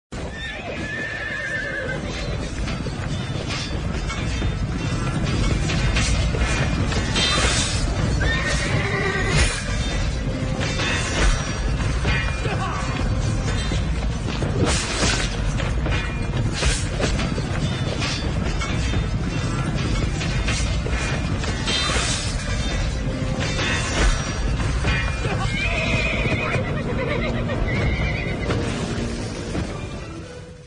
Sonneries » Sons - Effets Sonores » Bagarre